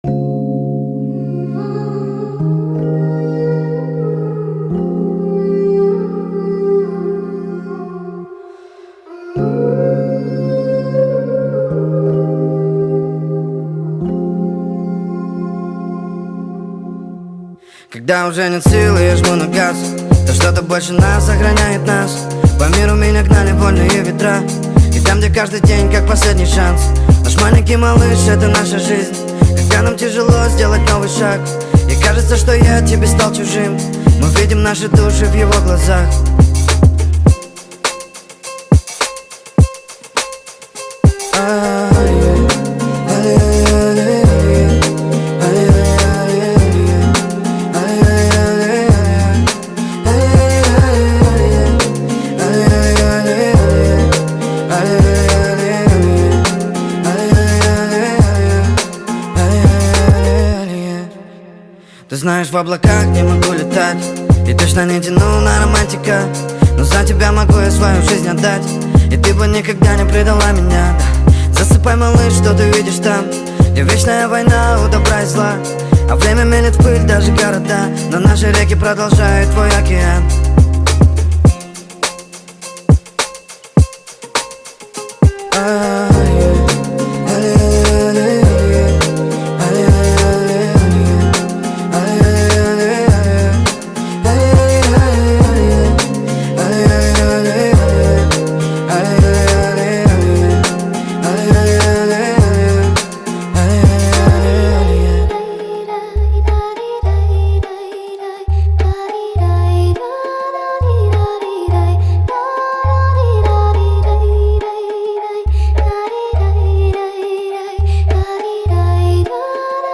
Speed Up TikTok Remix Trend 2025